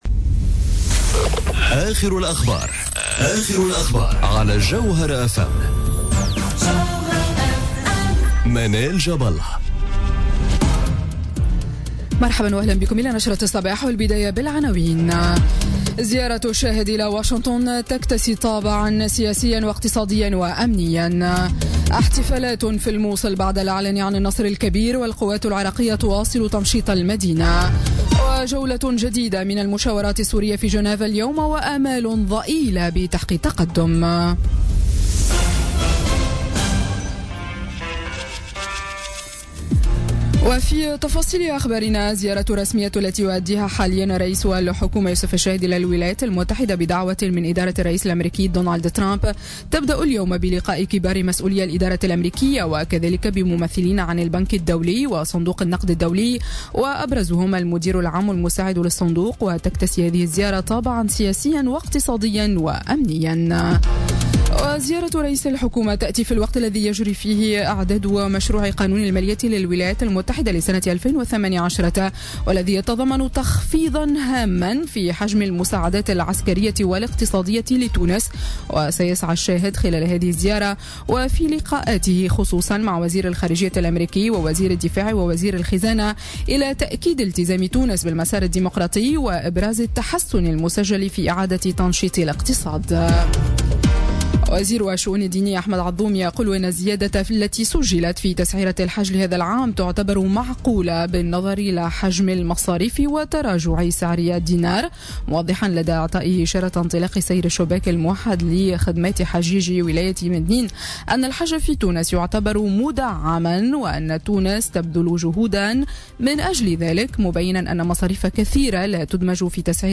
نشرة أخبار السابعة صباحا ليوم الإثنين 10 جويلية 2017